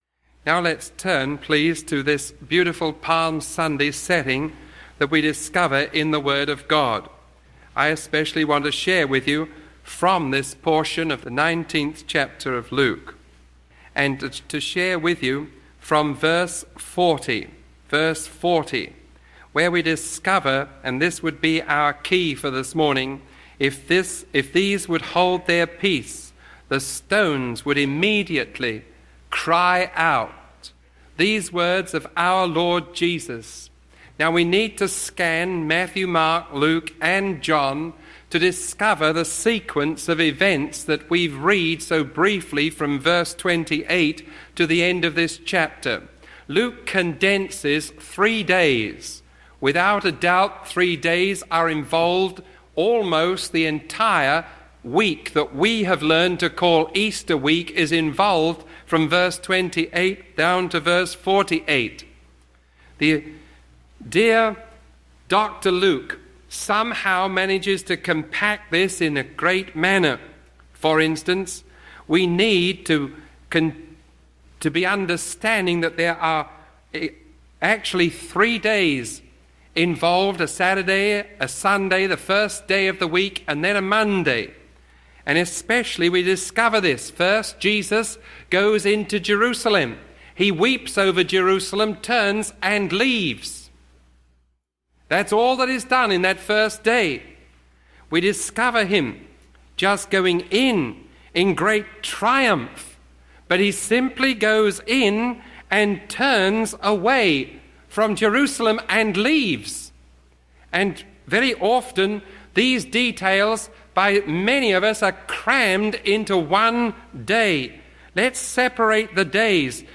Sermon 0039A recorded on April 8